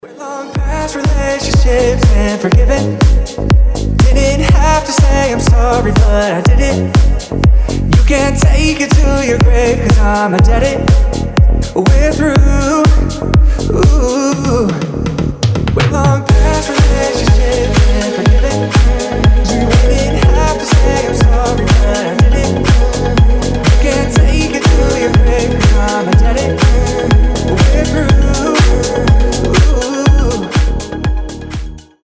• Качество: 320, Stereo
мужской голос
deep house
мелодичные
nu disco
Vocal House